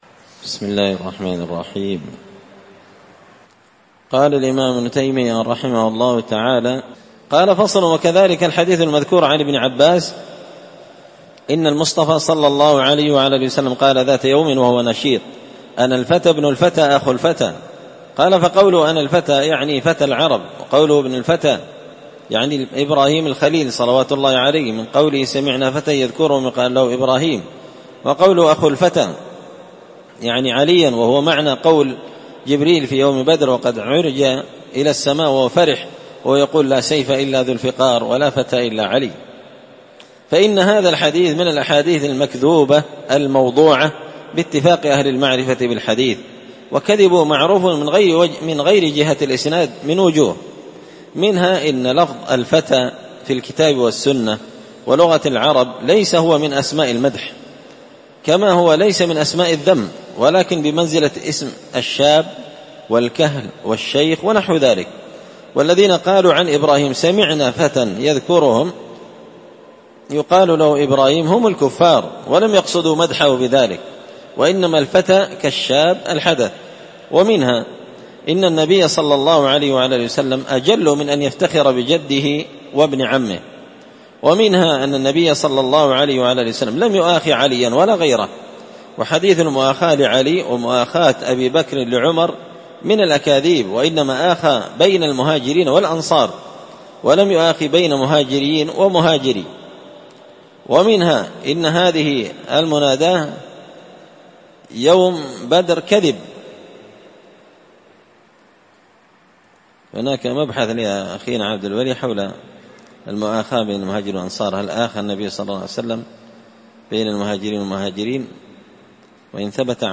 مختصر منهاج السنة النبوية لشيخ الإسلام ابن تيمية الحراني رحمة الله عليه ـ الدرس الثالث والتسعون (93) فصل في بيان كذب حديث أنا الفتى ابن الفتى أخو الفتى وكذب حديث أبي ذر في محبة علي
مسجد الفرقان قشن_المهرة_اليمن